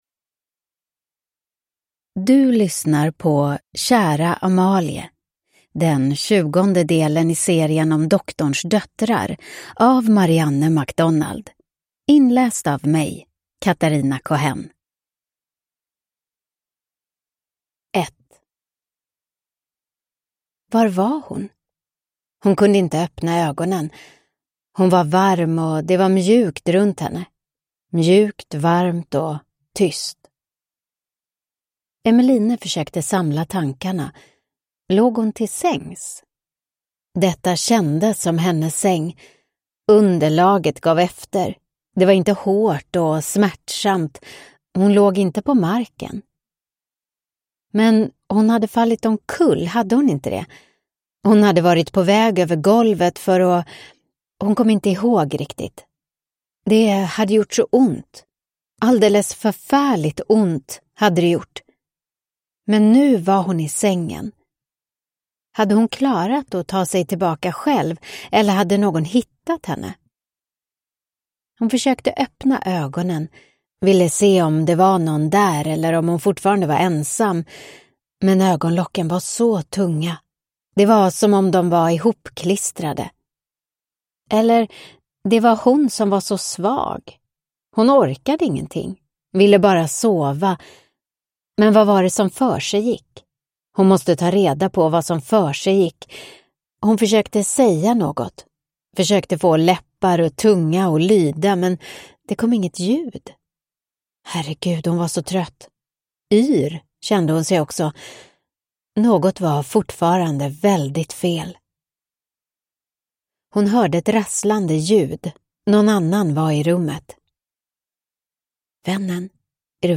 Kära Amalie (ljudbok) av Marianne MacDonald